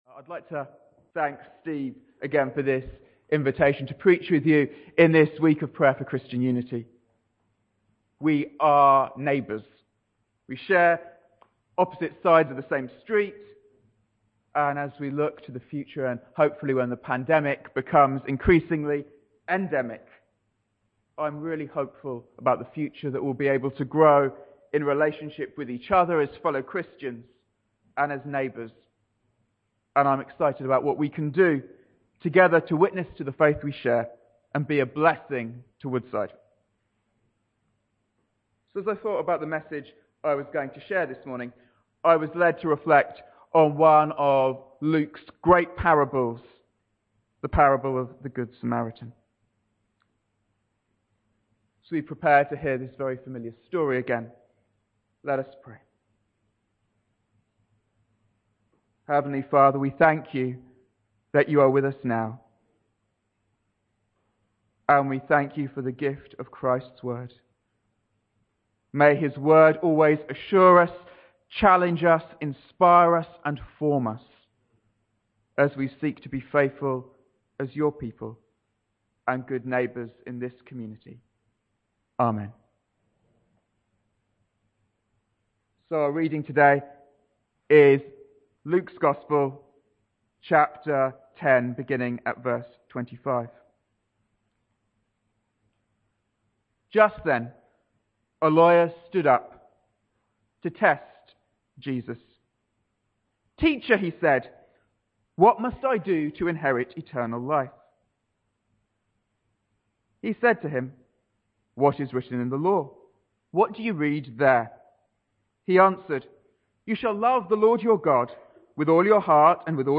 There is a transcript of this sermon available from our Sermon Archives page (see link below).